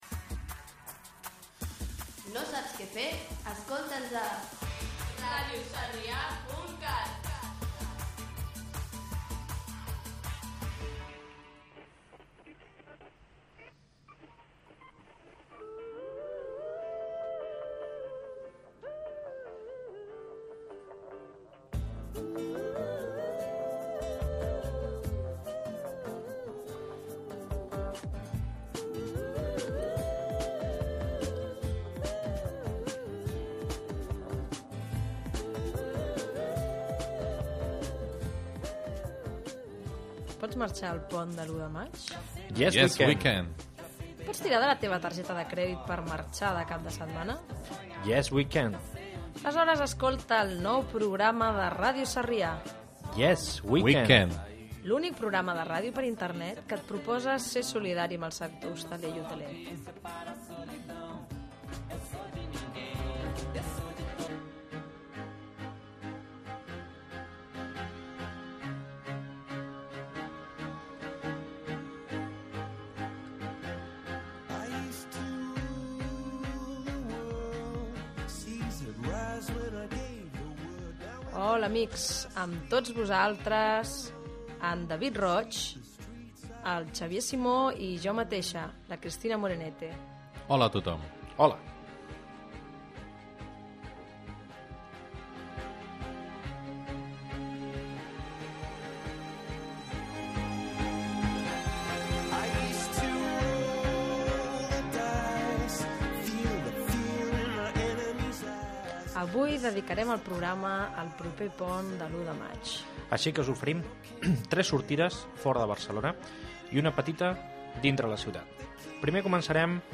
3a739c175c158c9199f27ac10a04af99073d92d5.mp3 Títol Ràdio Sarrià Emissora Ràdio Sarrià Titularitat Tercer sector Tercer sector Barri o districte Nom programa Yes weekend Descripció Indicatiu de la ràdio, promoció del programa, presentació de l'equip, propostes de viatges per al pont de l'1 de maig: París i Osca. Gènere radiofònic Entreteniment